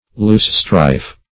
Loosestrife \Loose"strife`\ (l[=oo]s"str[imac]f`), n. (Bot.)